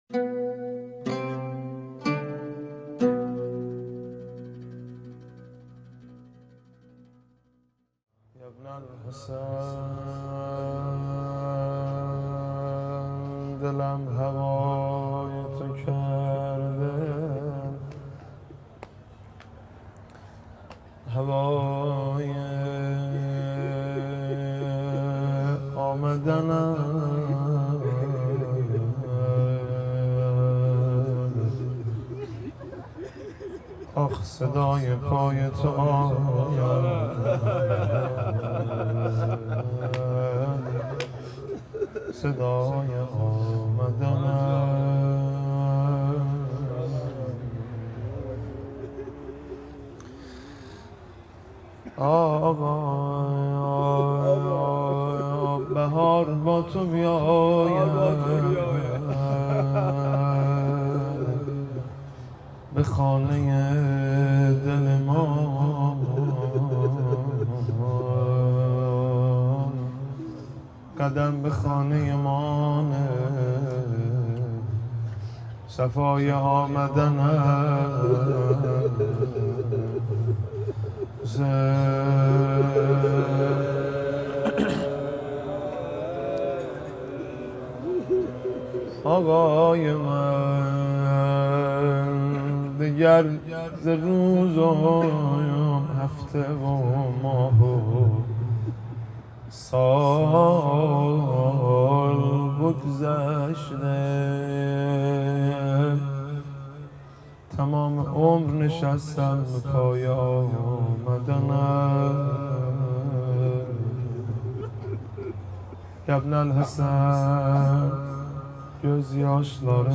روضه | ین الحسن دلم هوای تو کرده
روضه خوانی حاج مهدی رسولی | اجتماع زائران آذری‌زبان اربعین | 15 آبان 1396 - 6 نوامبر 2017 | مسیر نجف به کربلا، عمود 836 موکب فطرس